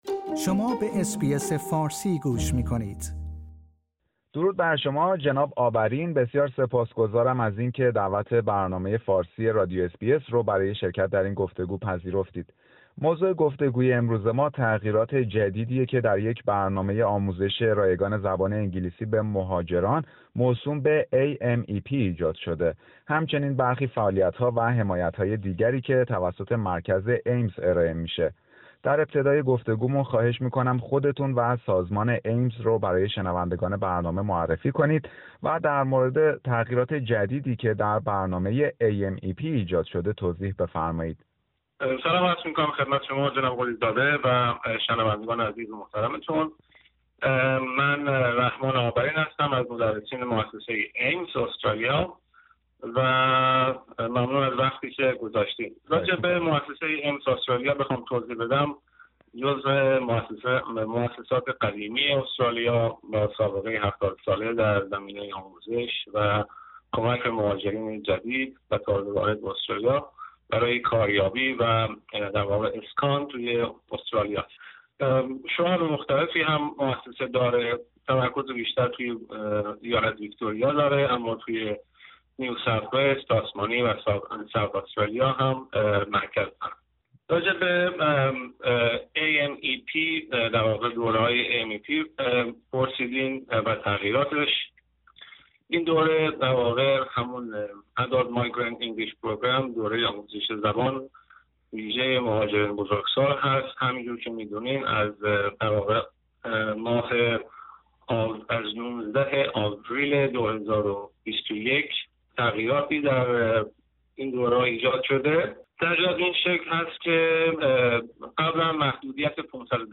گفتگویی در مورد نحوه دسترسی رایگان به کلاس های آموزش انگلیسی و خدمات اشتغال